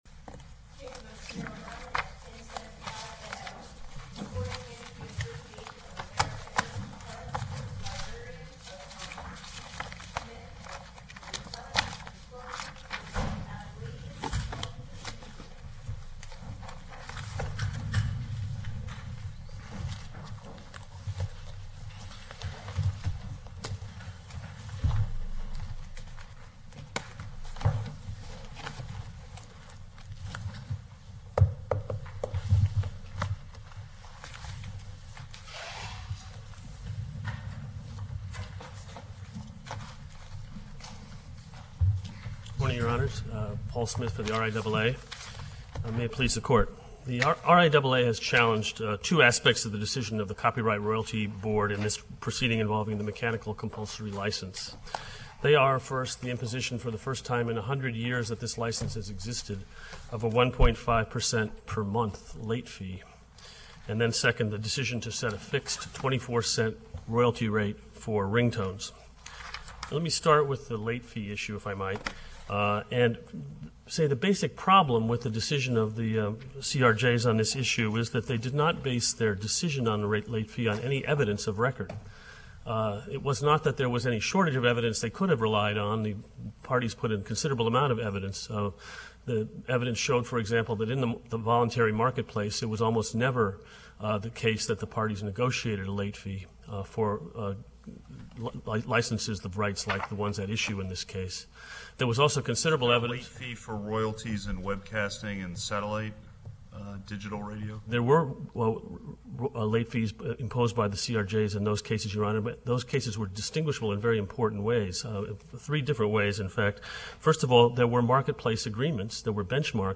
USCA-DC Oral Argument Recordings